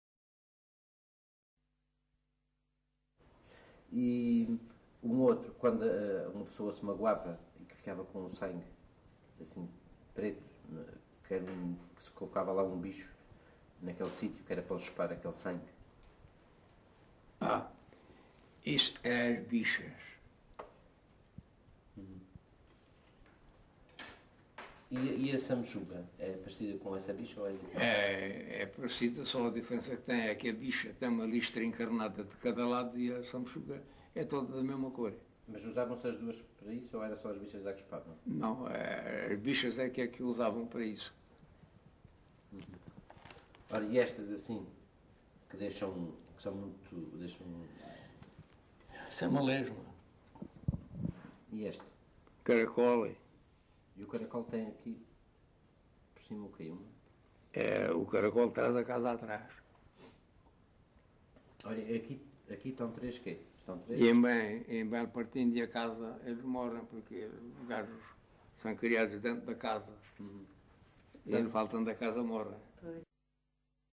LocalidadeCabeço de Vide (Fronteira, Portalegre)